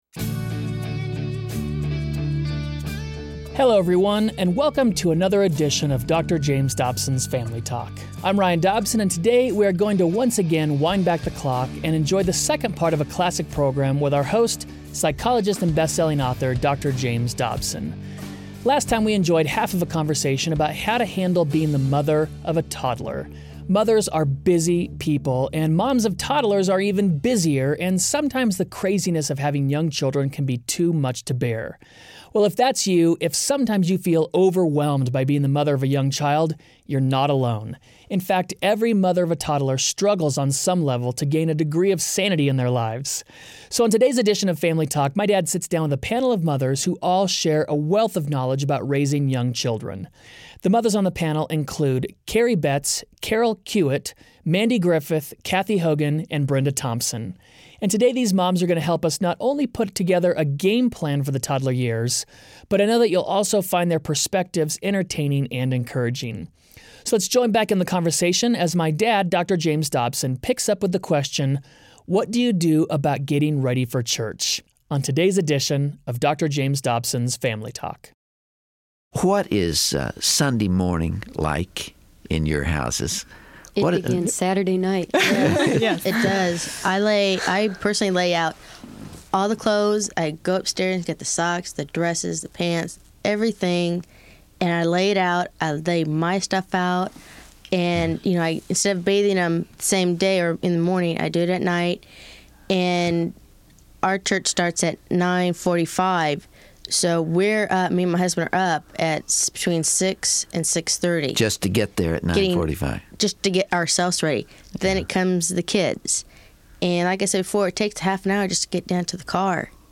On the next edition of Family Talk, Dr. James Dobson sits down with a panel of mothers who share a wealth of knowledge about raising young children.